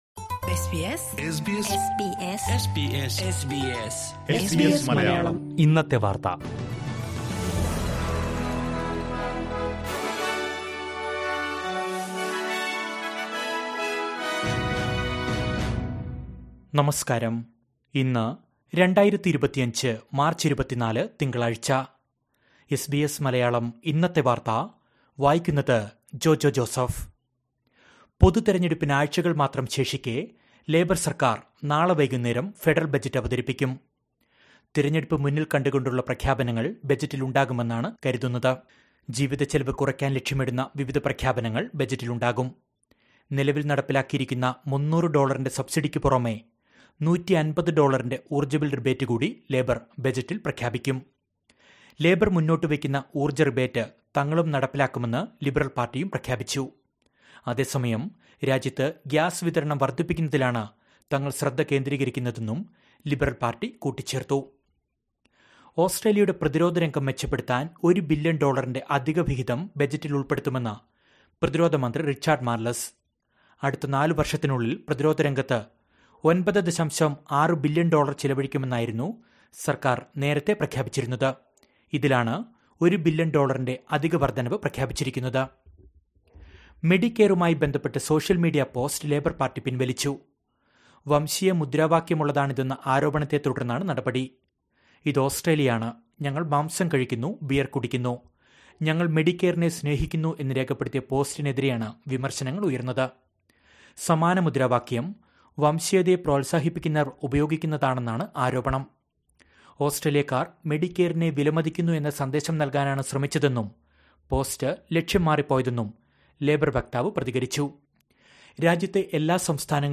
2025 മാർച്ച് 24ലെ ഓസ്‌ട്രേലിയയിലെ ഏറ്റവും പ്രധാന വാര്‍ത്തകള്‍ കേള്‍ക്കാം...